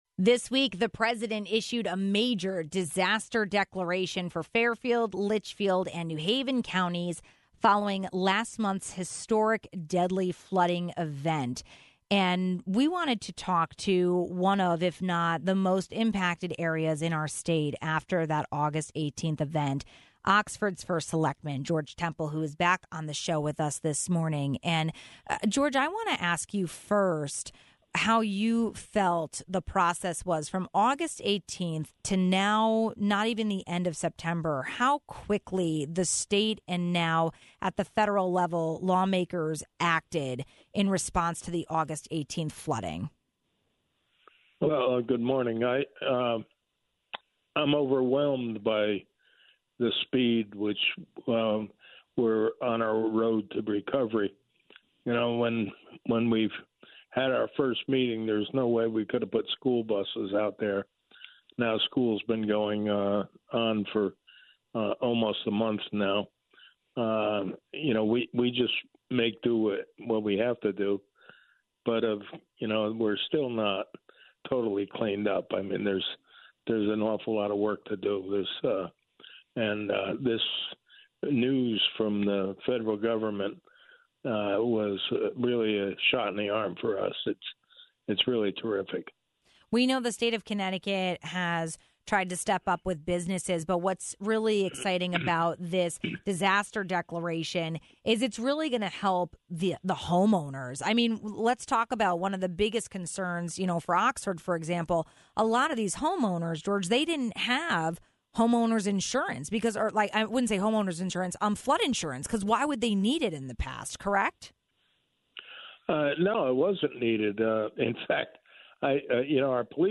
We spoke with Oxford First Selectman George Temple about the turnaround help since the deadly flood rocked his town.